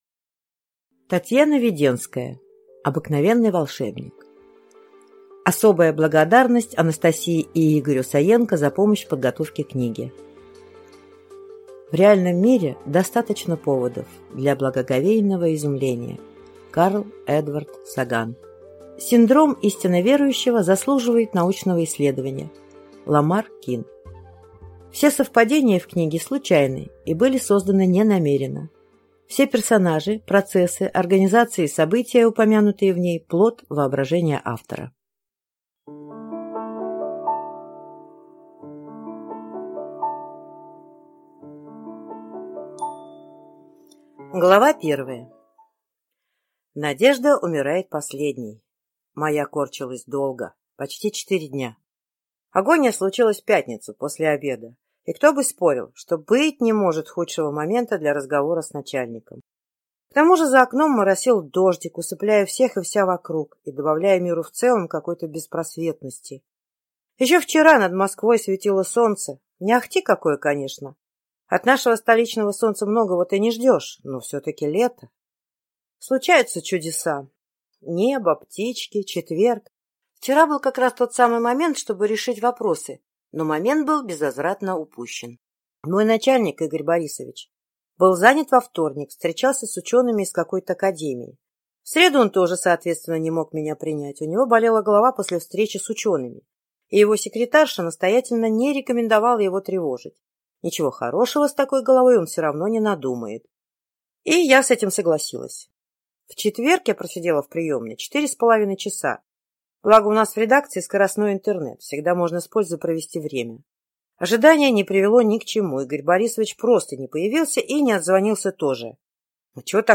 Аудиокнига Обыкновенный волшебник | Библиотека аудиокниг